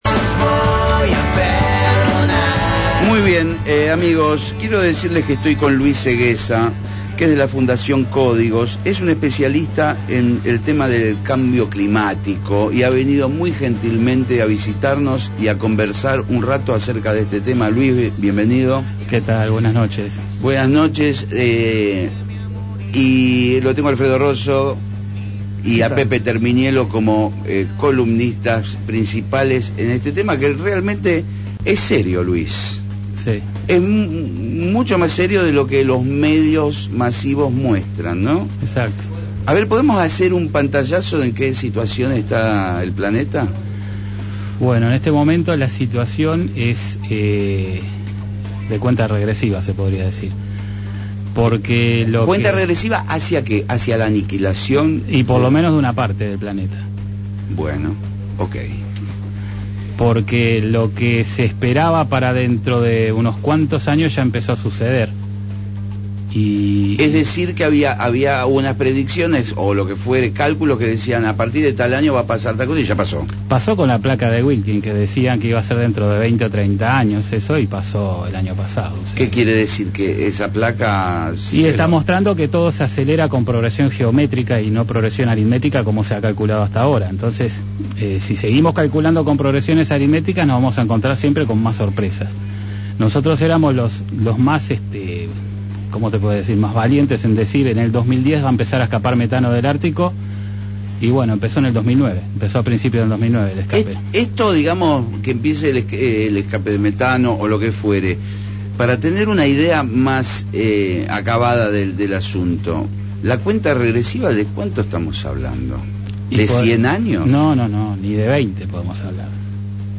Entrevista realizada el día 28 de octubre de 2009 en FM Rock & Pop de Buenos Aires, Argentina.